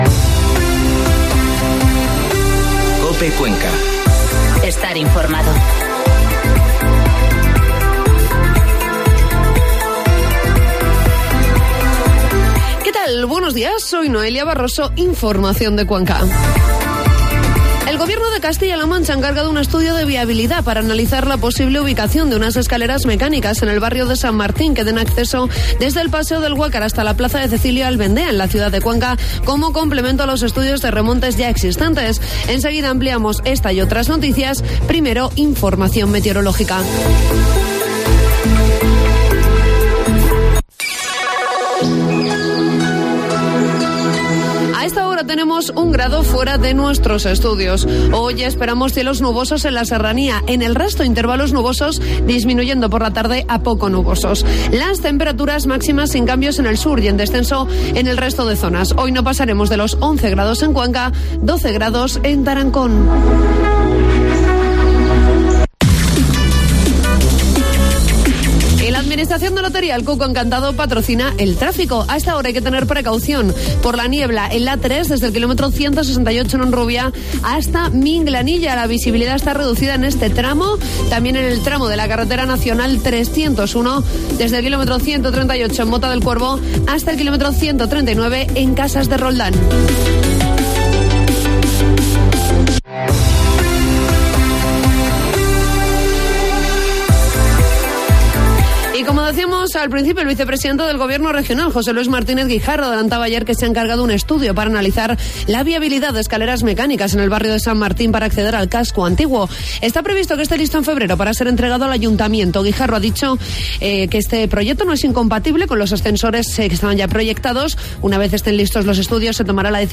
Informativo matinal COPE Cuenca 10 de enero